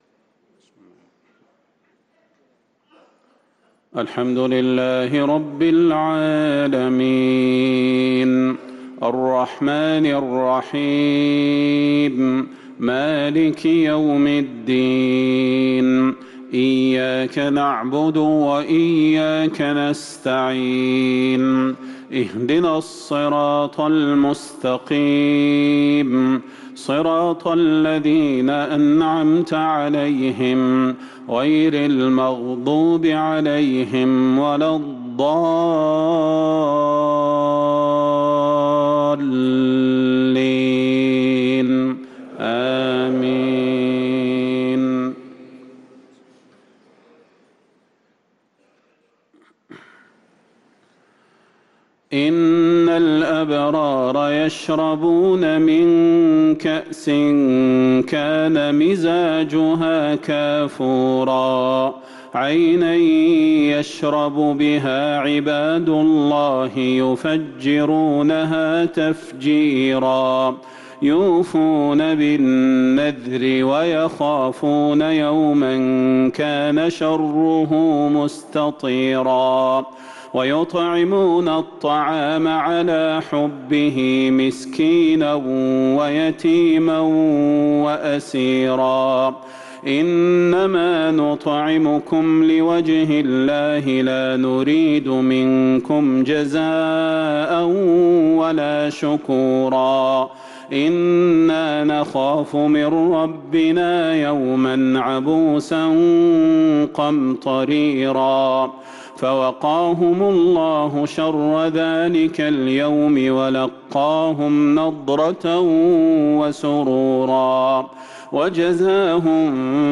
صلاة المغرب للقارئ صلاح البدير 23 ربيع الآخر 1445 هـ
تِلَاوَات الْحَرَمَيْن .